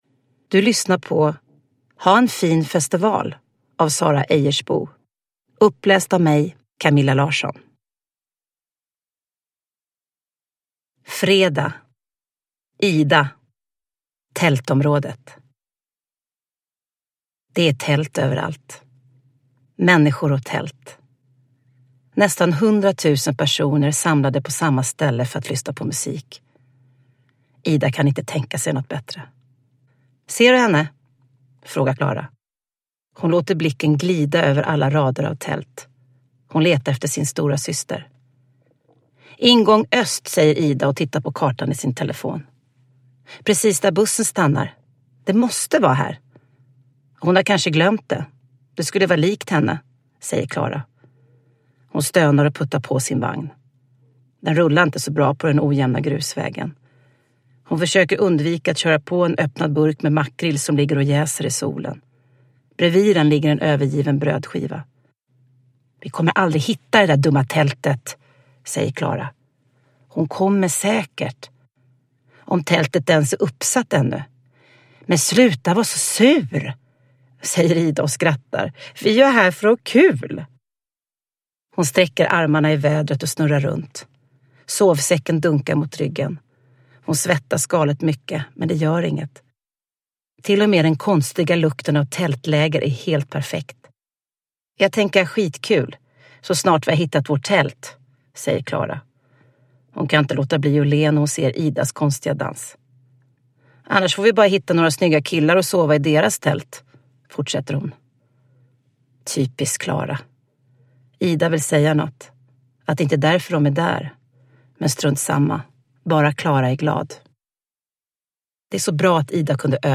Ha en fin festival! – Ljudbok